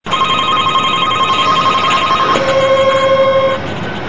（スマートフォン）   フライングカーペットのスタート音　4秒